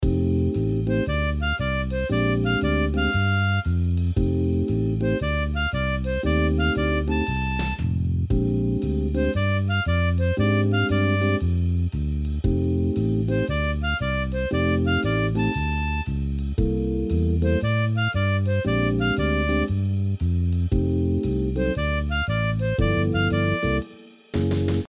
How to play great riffs on a diatonic harp without bending ?
Let’s have a look at the example below, based on a 12 bar blues progression.
Tempo is 116, eight notes are played swing style, not exactly as written.
Example 3, making riff 1 longer, and changing some notes (note : on bars 30, 34 and 36, notes and rhythm are exactly the same) :